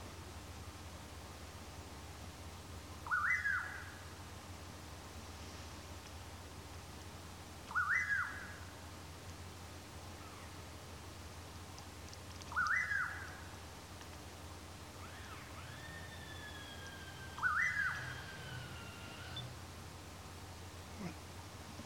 Bacurau (Nyctidromus albicollis)
Nome em Inglês: Pauraque
Localidade ou área protegida: Villa Ocampo
Condição: Selvagem
Certeza: Gravado Vocal
curiango-voz.mp3